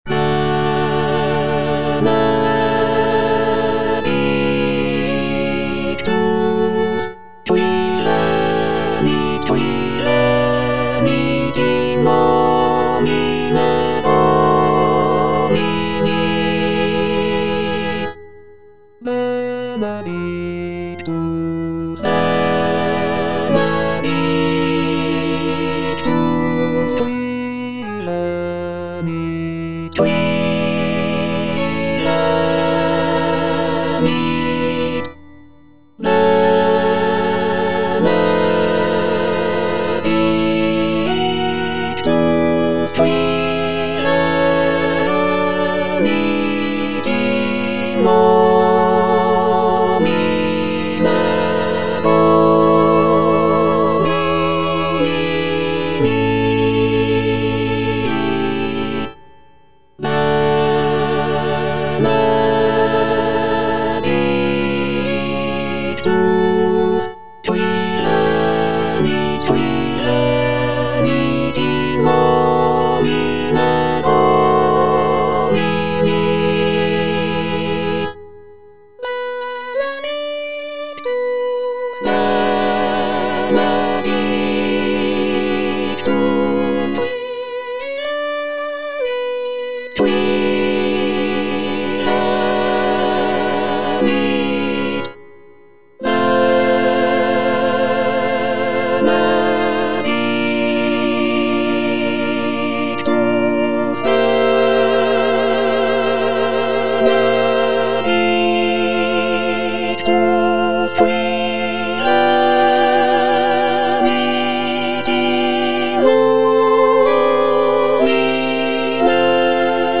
F. VON SUPPÈ - MISSA PRO DEFUNCTIS/REQUIEM Benedictus (soprano II) (Emphasised voice and other voices) Ads stop: auto-stop